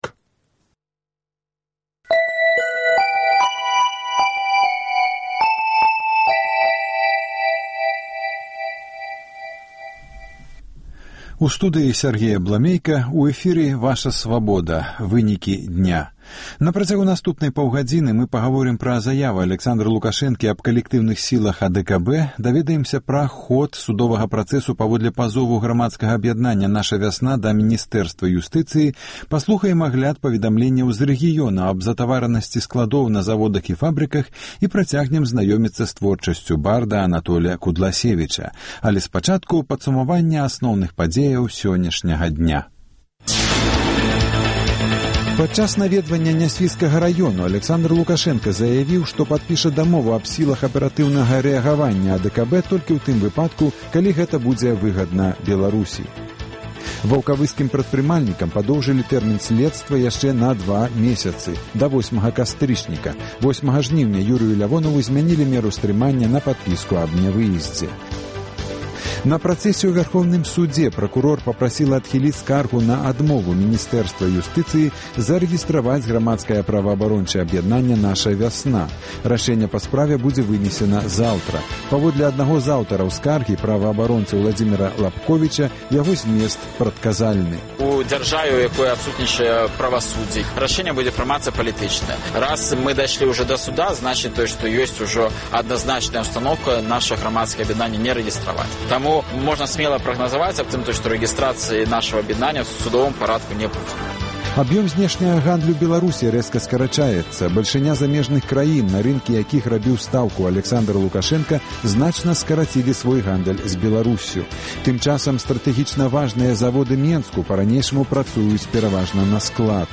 Паведамленьні нашых карэспандэнтаў, званкі слухачоў, апытаньні ў гарадах і мястэчках Беларусі.